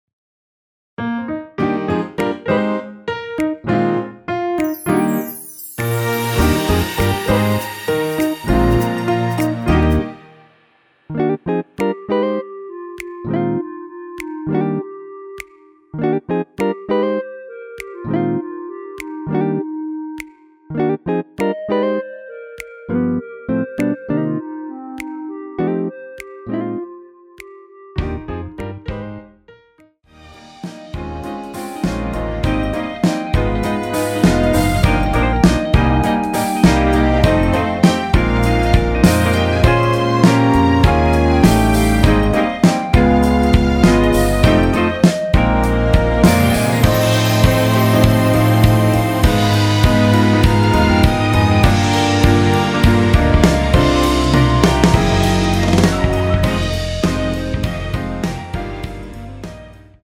원키 멜로디 포함된 MR 입니다.(미리듣기 참조)
Eb
앞부분30초, 뒷부분30초씩 편집해서 올려 드리고 있습니다.
중간에 음이 끈어지고 다시 나오는 이유는